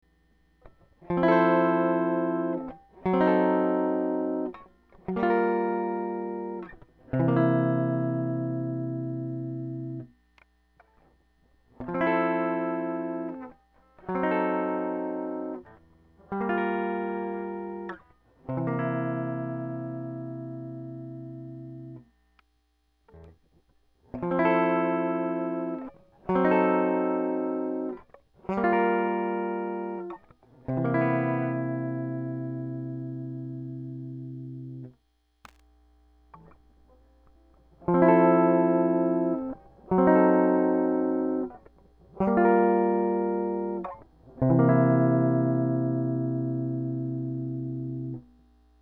ネック側単独だと太めのシングルコイルって感じでなかなかいい感じ。
ただ、直列接続はパワフルすぎてちょっと苦手かもです。
所有のLEAD2にLEAD1のアッセンブリーを載せて録音してみました。PCに直接アレしてネック側コイル、ブリッジ側コイル、ダブルコイル並列、同じく直列、の順です。
lead1_maple.mp3